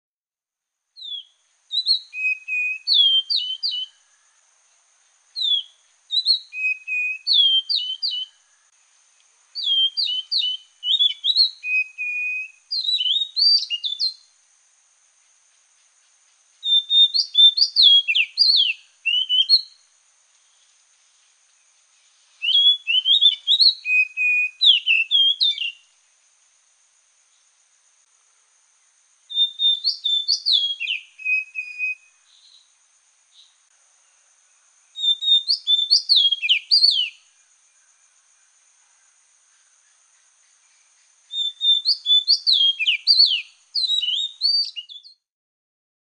Oriental Magpie Robin – advertising songs
10-Oriental-Magpie-robin.mp3